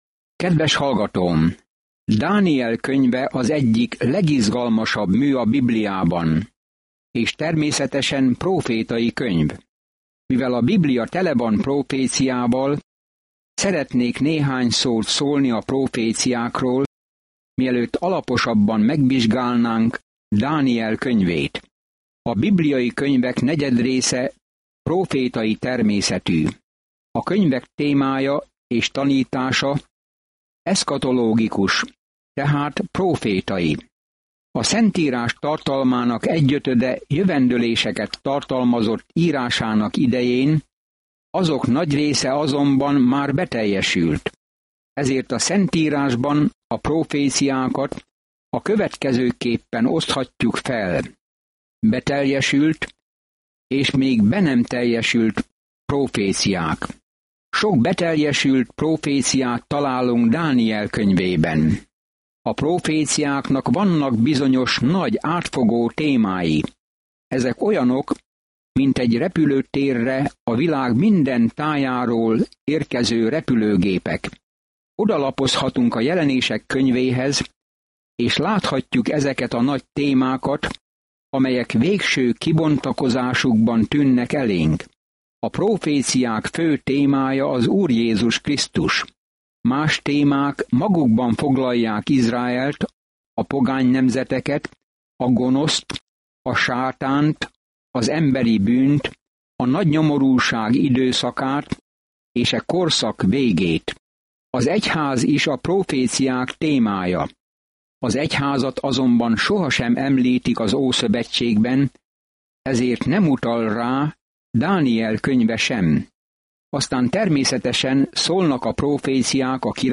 Szentírás Dániel 1:1-2 Olvasóterv elkezdése Nap 2 A tervről Dániel könyve egyszerre egy olyan ember életrajza, aki hitt Istenben, és egy prófétai látomás arról, hogy ki fogja végül uralni a világot. Napi utazás Dánielen keresztül, miközben hallgatod a hangos tanulmányt, és olvasol válogatott verseket Isten szavából.